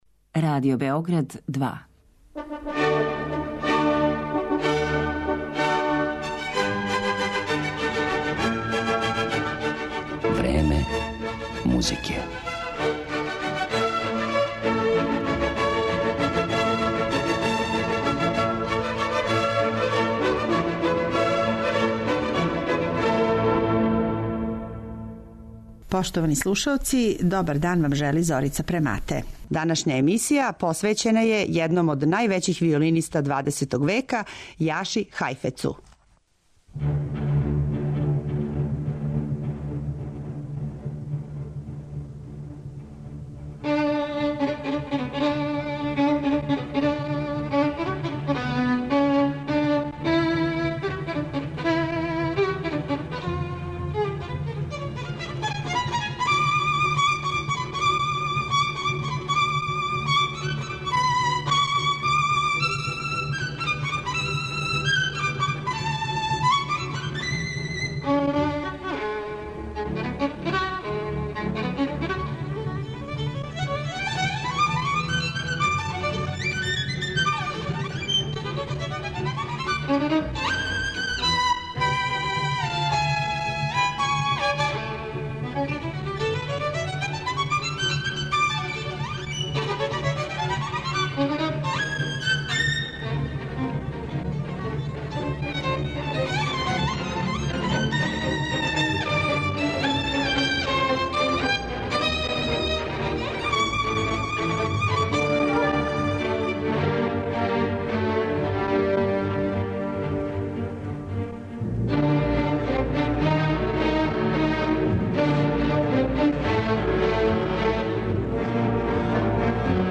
Емитоваћемо његове снимке на којима изводи дела Сибелијуса, Менделсона, Вијетана, Рахмањинова и Бетовена.